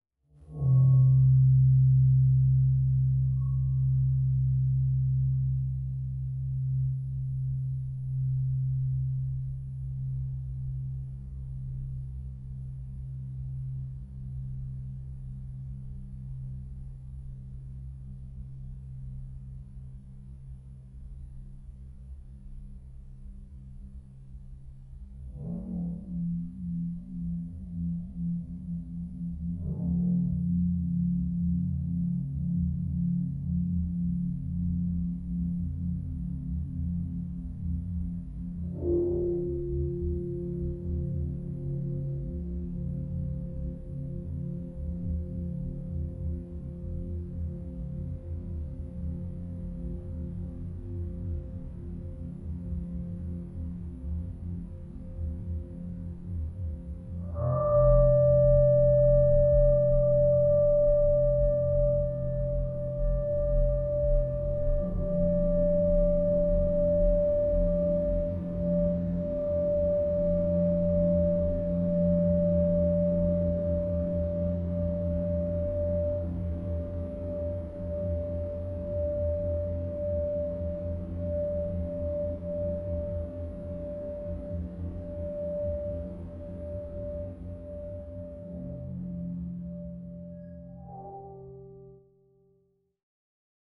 Below is an audio file of an analysis, without smoothing, played back down an octave at 10x the length.
Because the audio is slowed down but the analysis has no smoothing, you’ll hear that frequencies and amplitudes develop at a faster rate and causes, to my ear, digitization noise.